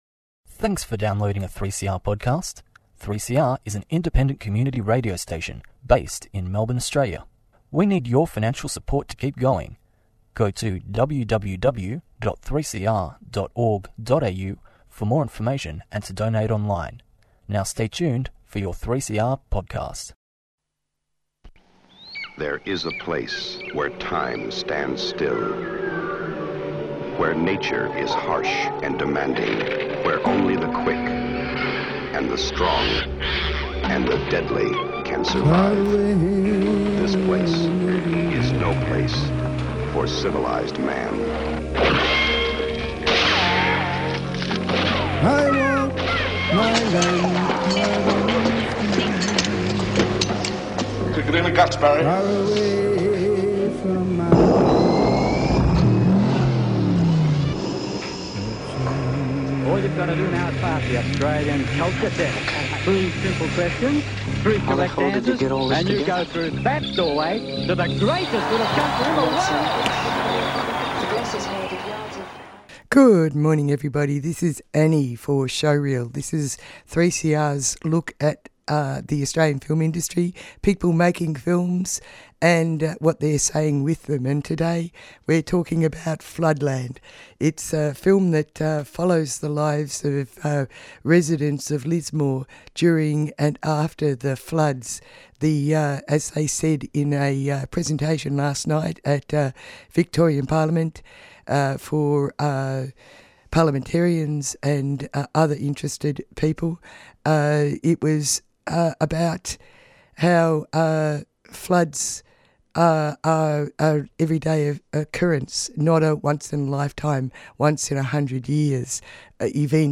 Tweet Showreel Thursday 11:00am to 11:30am Your half hour of local film news, conversations with film makers and explorations into how they bring their ideas to life on screen.